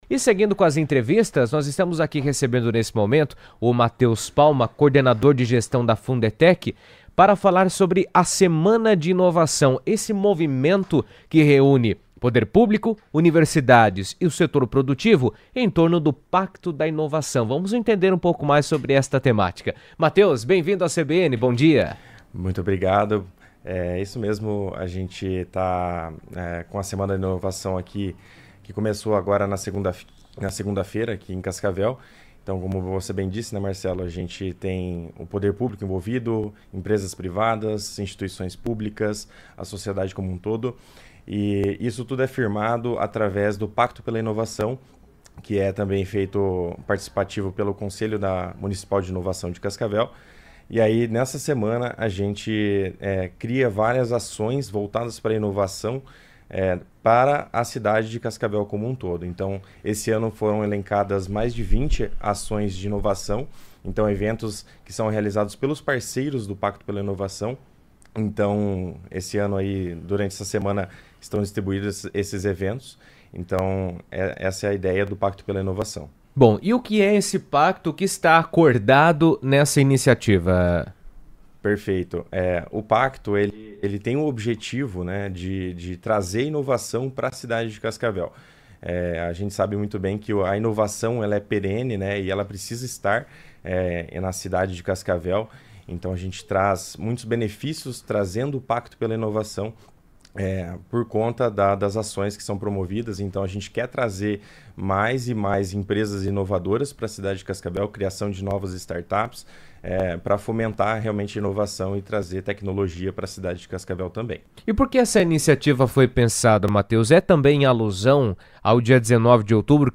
A Semana de Inovação promove a integração entre poder público, universidades e setor produtivo, com foco no Pacto da Inovação, iniciativa que busca fortalecer a cooperação e o desenvolvimento tecnológico no Paraná. Em entrevista à CBN